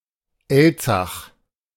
Elzach (German pronunciation: [ˈɛlt͡sax]